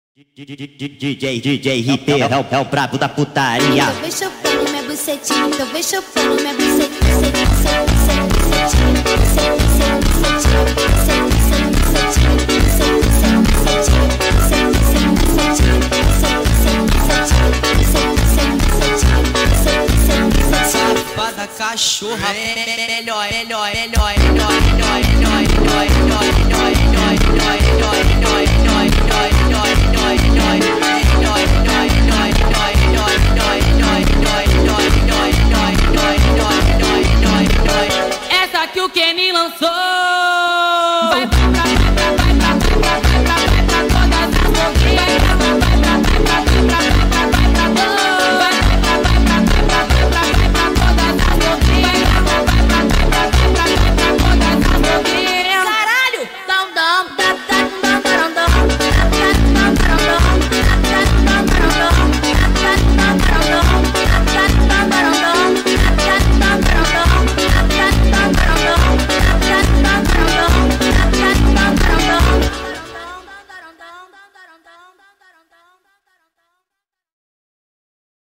فانک با ریتم تند شده
فانک